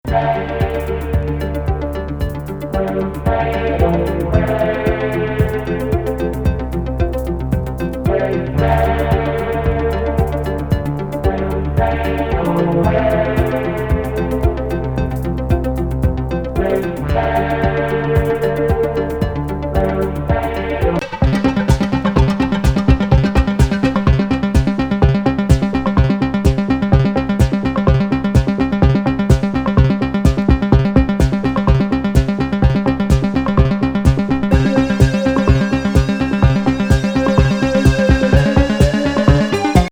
程よいイーブン・キックに宇宙SE飛び交うコズミック・ナンバー